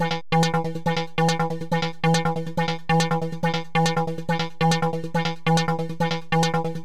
Distort Loops » Metalic Slam
描述：Sound made in Sylenth 1 with Third Party Virtual Distortion units.
标签： Electronic Dubstep Ambient Cinematic Music Free Travel Movie Classical DJ Sound
声道立体声